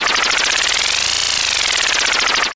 SFX快速旋转飞旋法术游戏技能释放音效下载
SFX音效